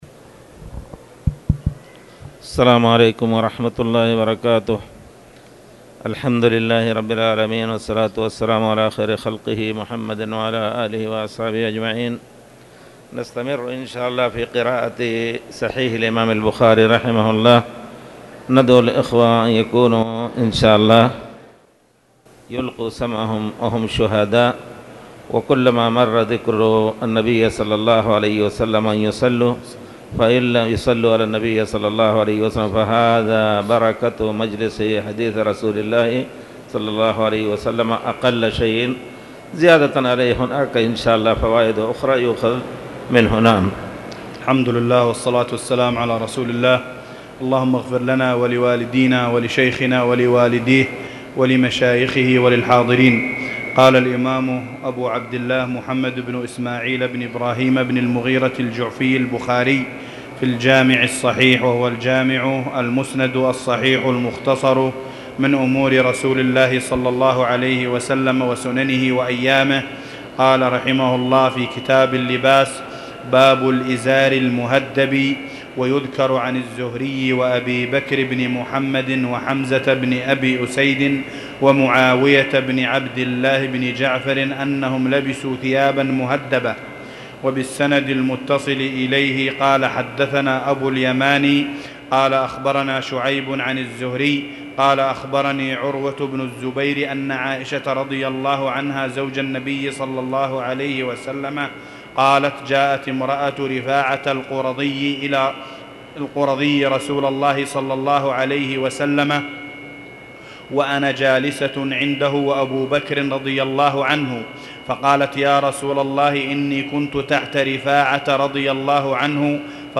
تاريخ النشر ٢ ربيع الثاني ١٤٣٨ هـ المكان: المسجد الحرام الشيخ